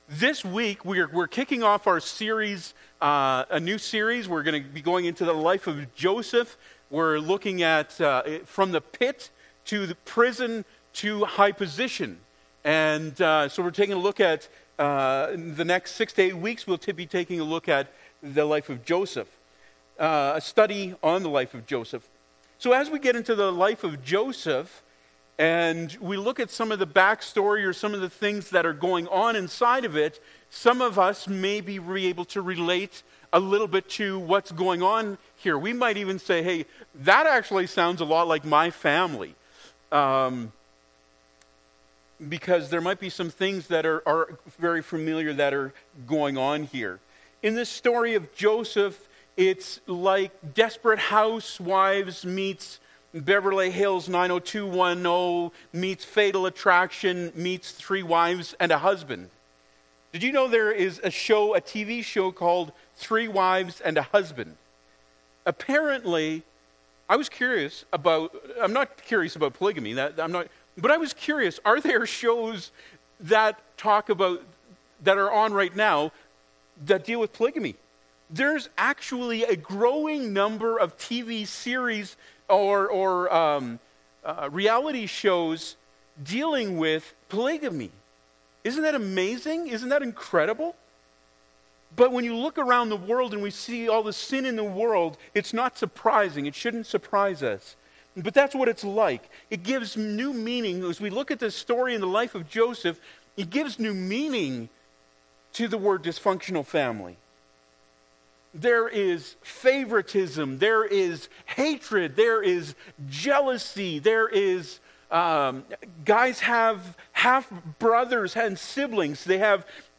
The Joseph Series Service Type: Sunday Morning Preacher